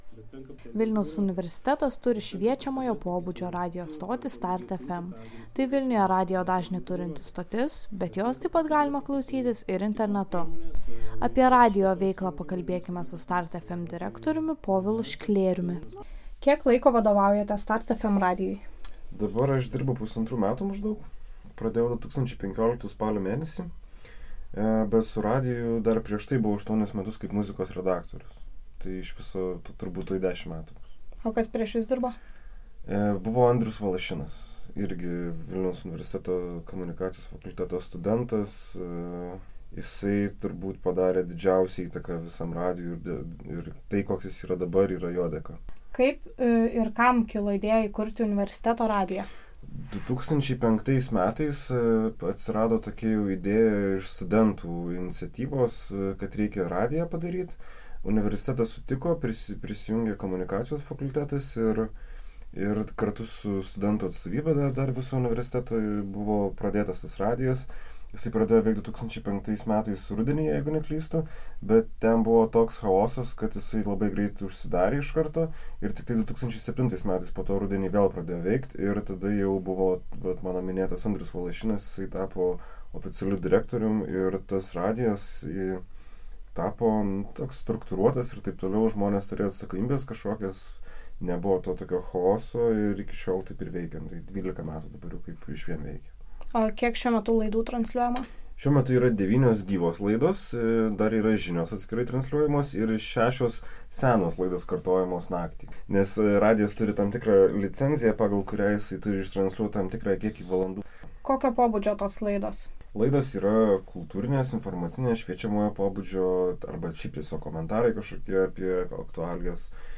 Studentės iš anapus stiklo papasakos, ar radijas yra naudinga patirtis ir ko jame galima išmokti.